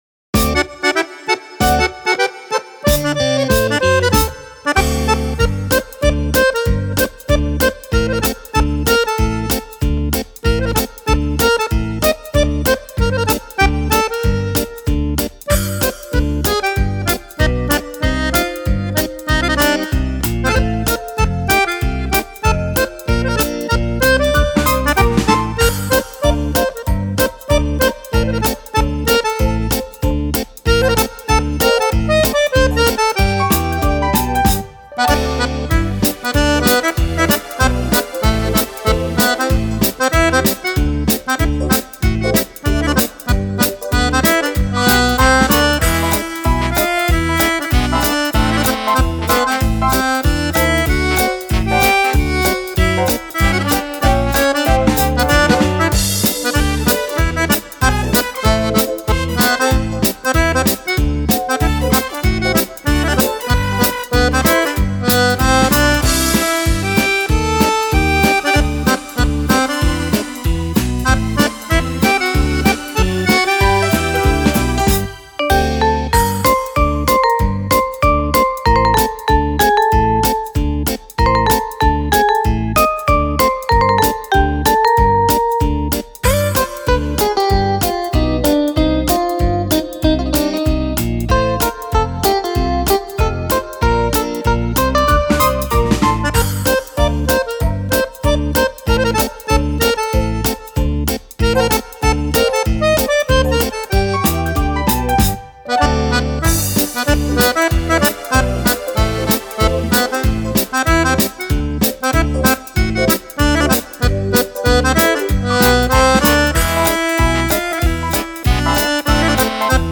Fox
Fox per Fisarmonica